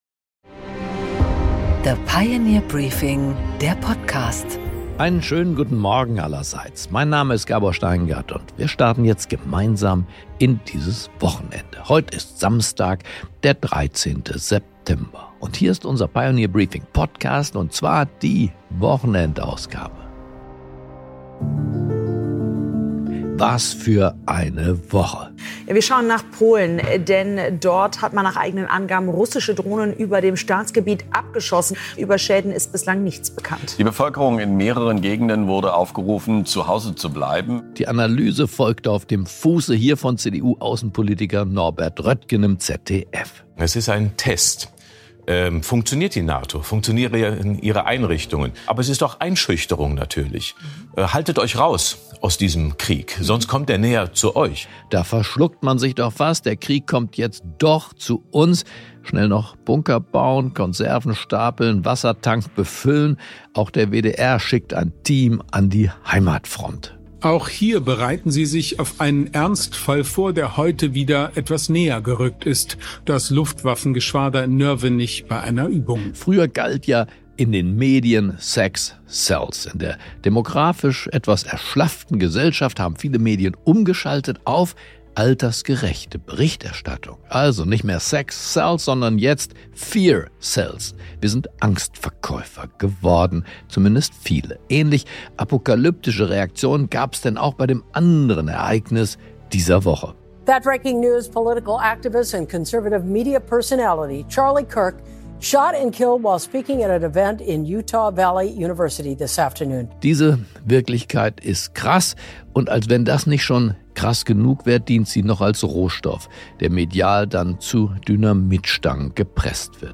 Gabor Steingart präsentiert die Pioneer Briefing Weekend Edition